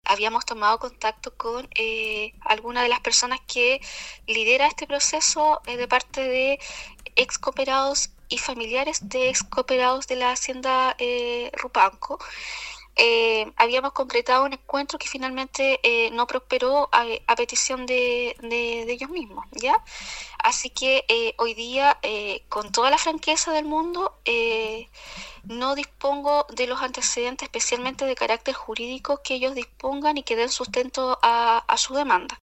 En entrevista con Radio “Sago”, la delegada presidencial en la provincia de Osorno, Claudia Pailalef, se refirió al desalojo registrado anoche en la comuna de Puerto Octay. La autoridad recalcó que se trata de un conflicto entre particulares que debe ser solucionado mediante la vía del diálogo.